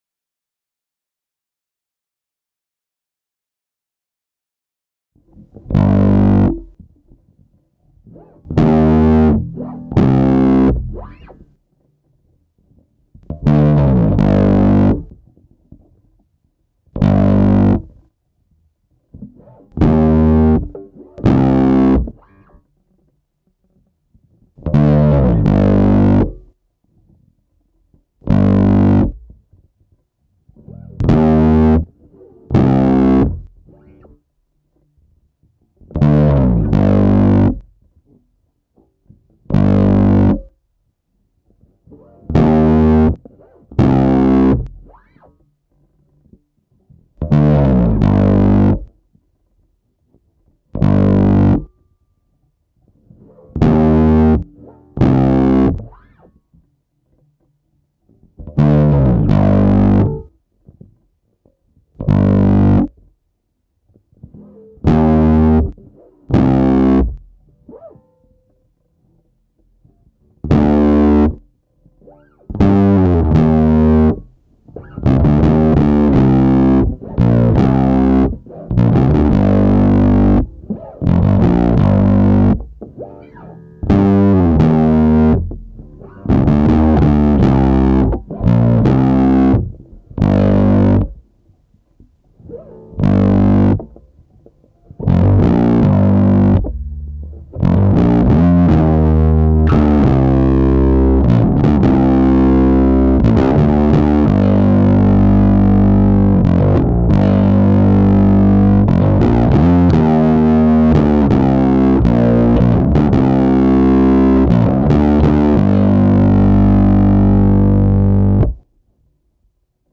(nur bass)